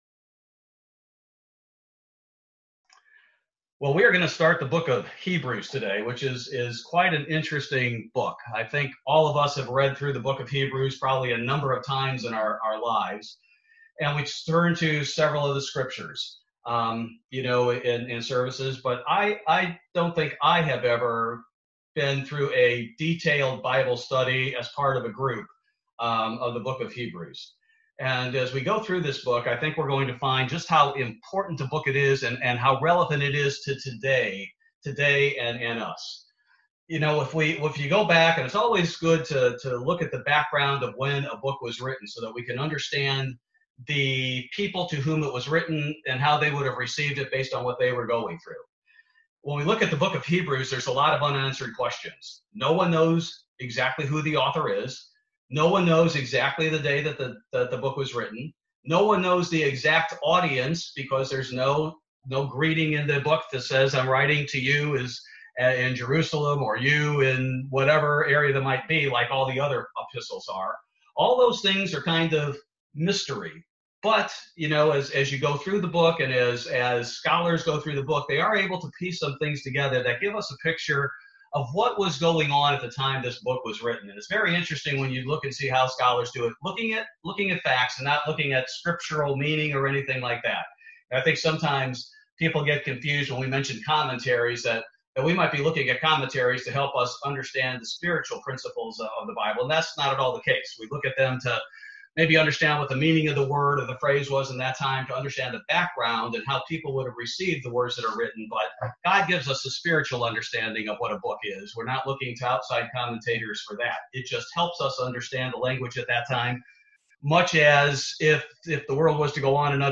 Bible Study - October 28, 2020